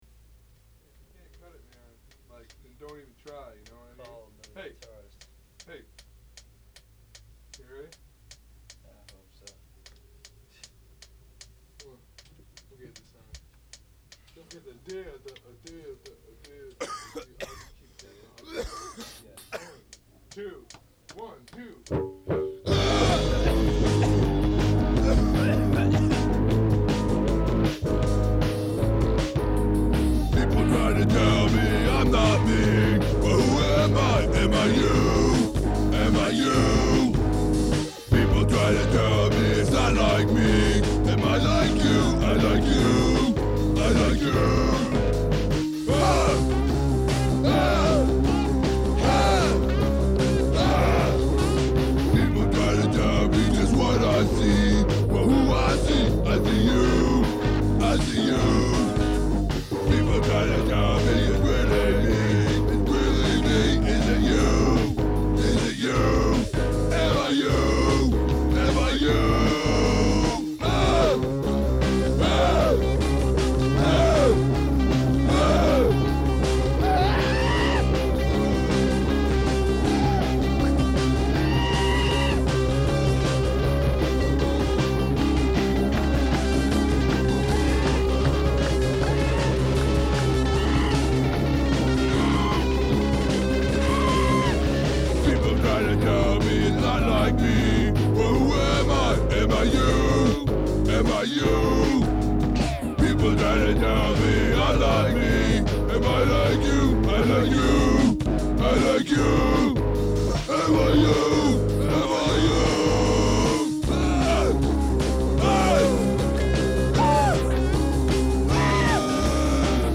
I think there are just two dissonant notes, half steps.  A few chords.
Lots of sarcasm and screaming. The chorus is just shouting HA four times.